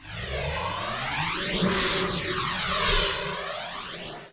lock1.ogg